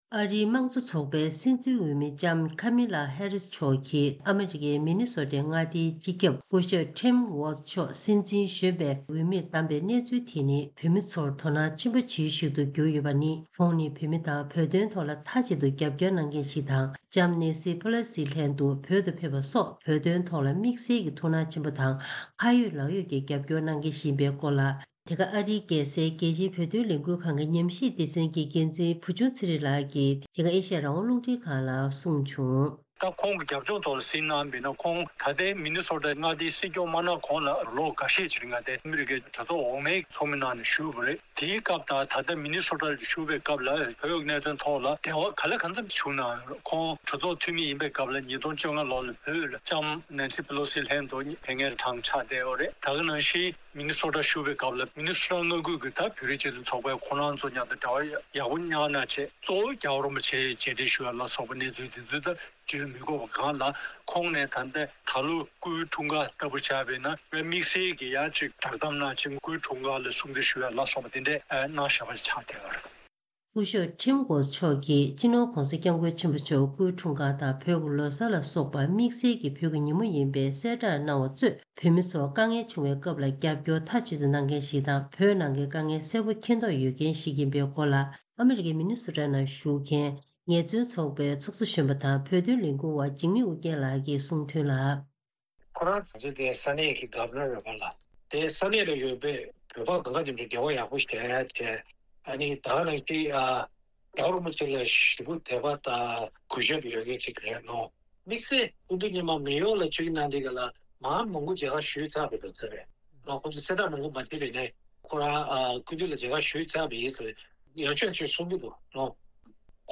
སྒྲ་ལྡན་གསར་འགྱུར།
ཐེངས་འདིའི་གསར་འགྱུར་དཔྱད་གཏམ་གྱི་ལེ་ཚན་ནང་།